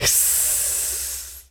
snake_hiss_10.wav